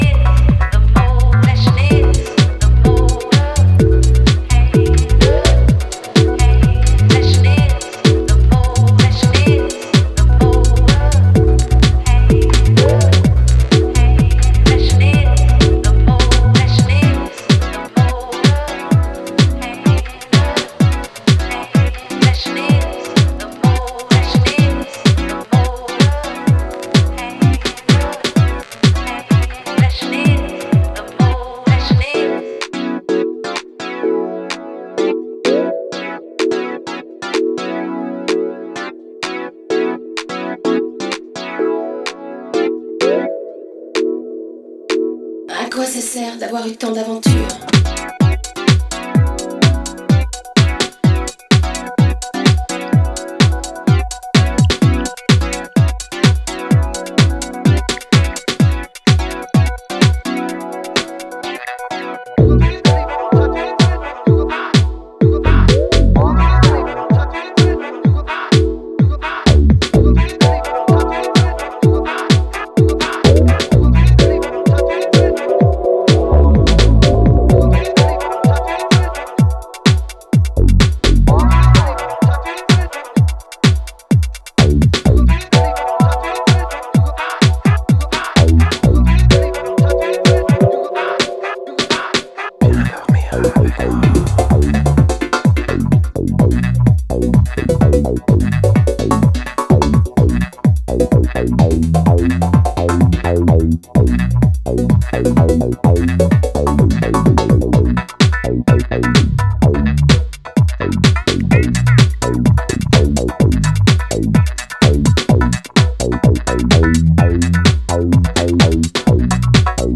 a mesmerizing soundscape with evolving recurring motifs